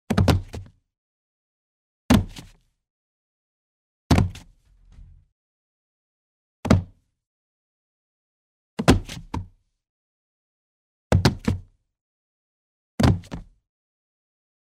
Звуки падения человека
Падение человека на деревянный пол
Человек падает на деревянную поверхность